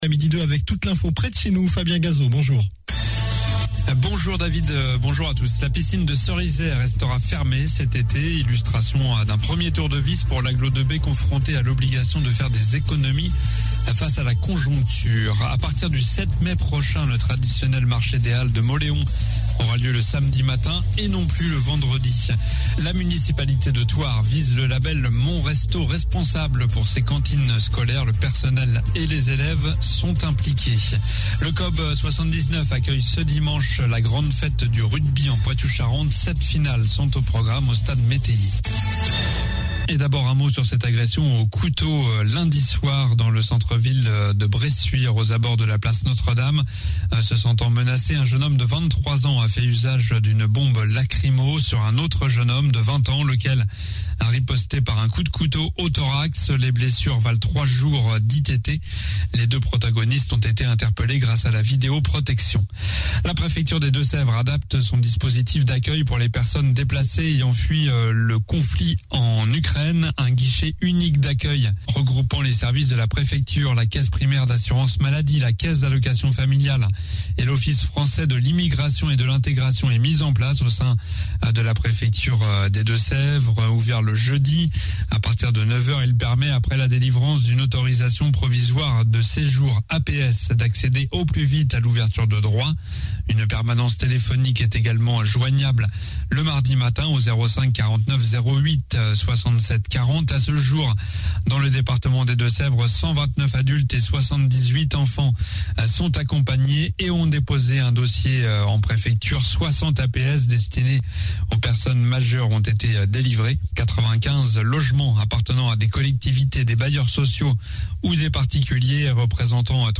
Journal du jeudi 14 avril (midi)